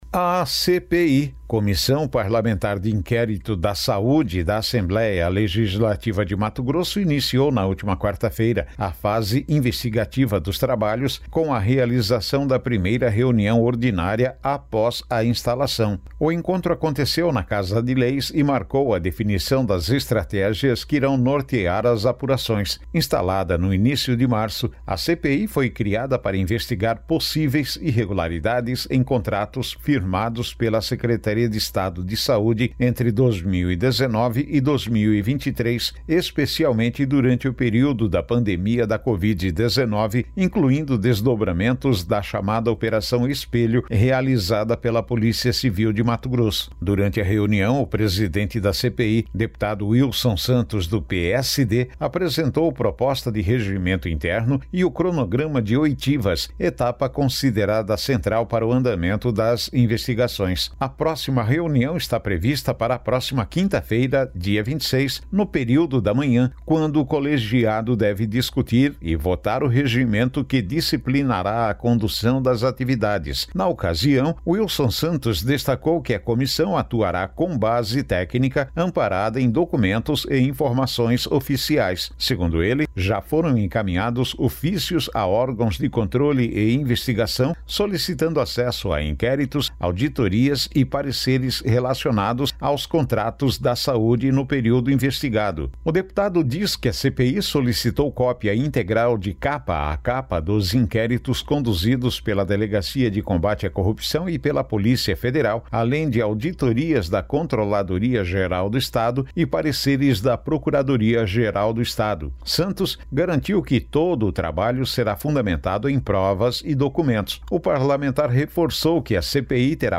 Boletins de MT 20 mar, 2026